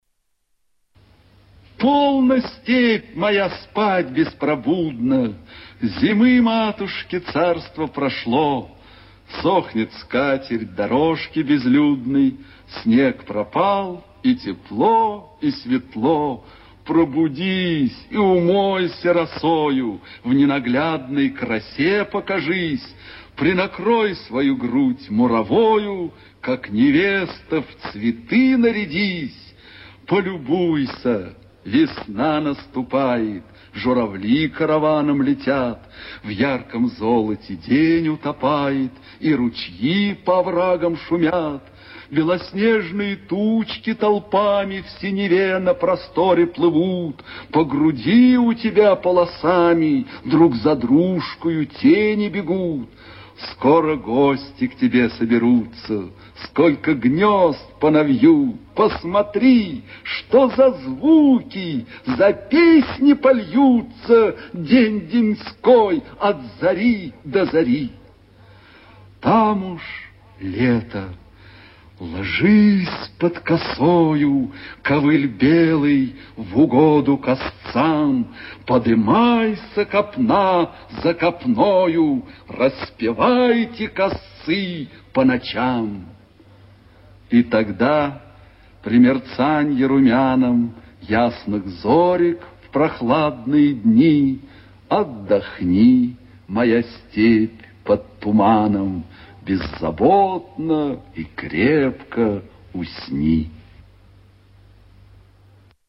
Прослушивание аудиозаписи стихотворения «Полно, степь моя, спать беспробудно...» с сайта «Старое радио»